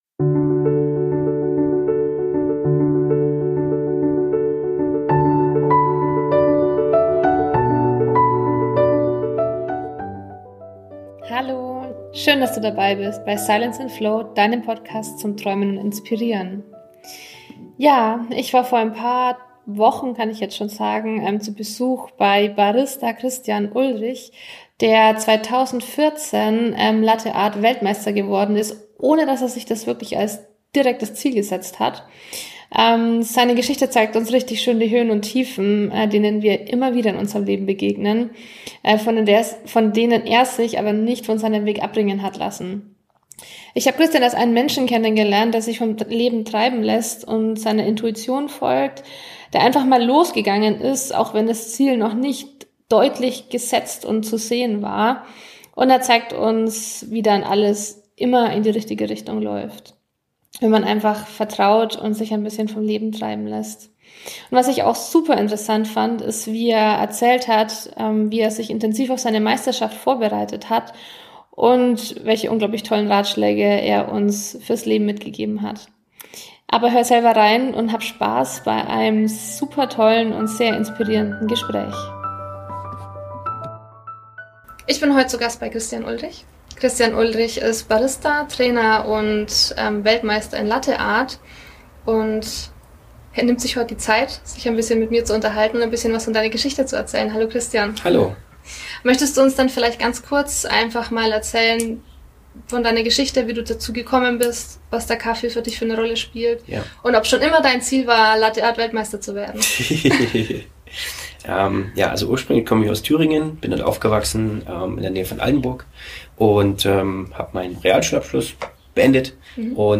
Aber hört selbst in dieses spannende und inspirierende Interview herein.